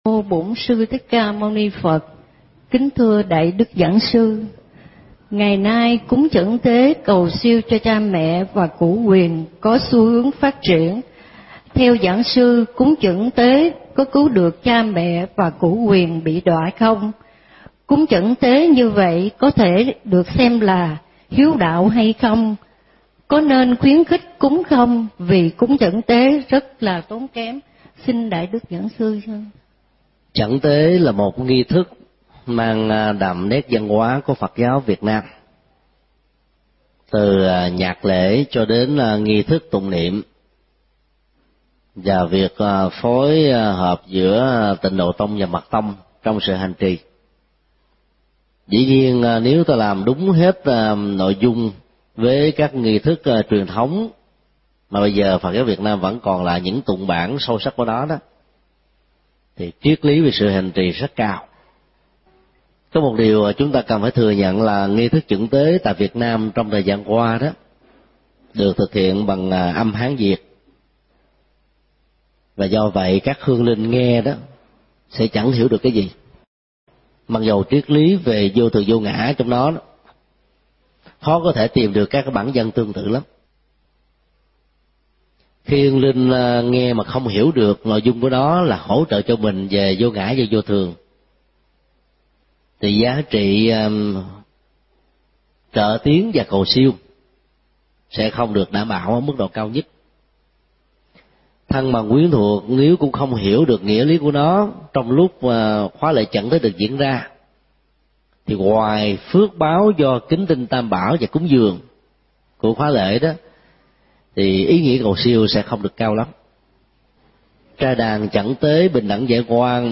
Vấn đáp: Cúng chuẩn tế cho cha mẹ đã khuất – Thầy Thích Nhật Từ mp3